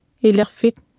Speech Synthesis Martha